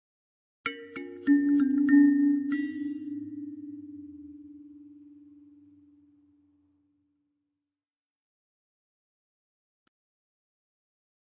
Kalimba, Accent, Type 2